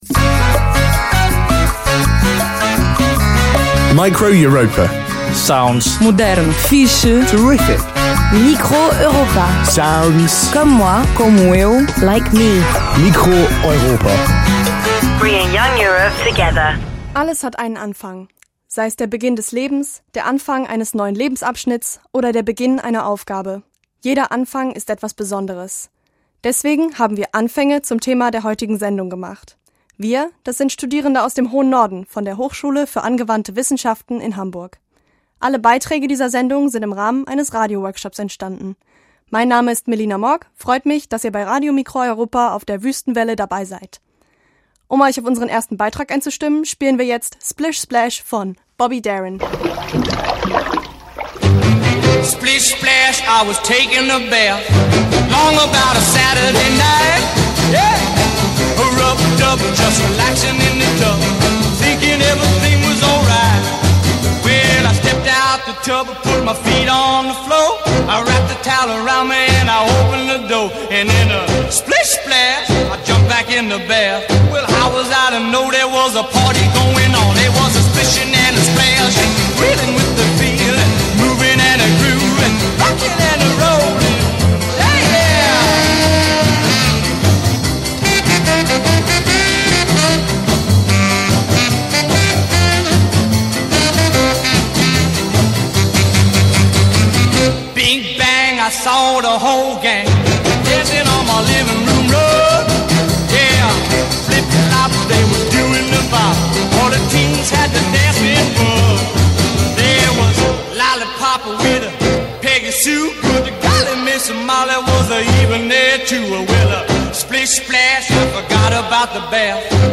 Die heutige Sendung wurde nicht in Tübingen, sondern im hohen Norden an der Hamburger Hochschule für angewandte Wissenschaften (HAW) im Rahmen eines Radio-Workshops produziert.